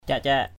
/ca:ʔ-ca:ʔ/